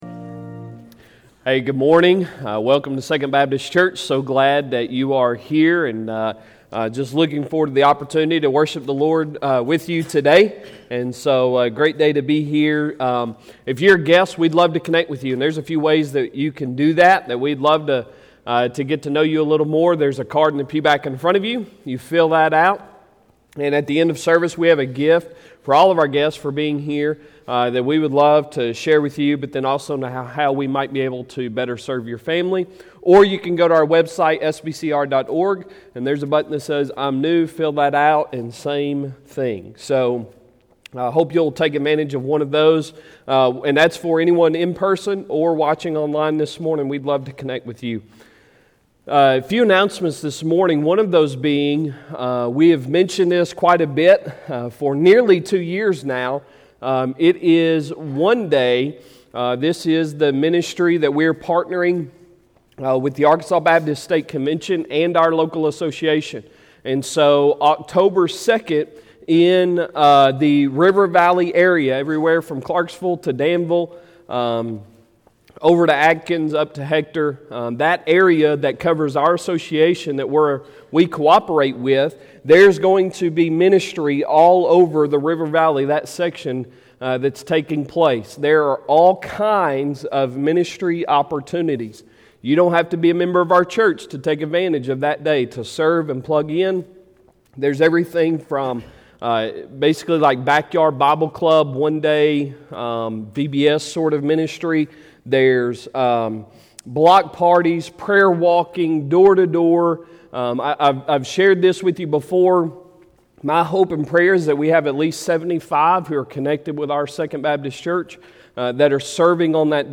Sunday Sermon August 29, 2021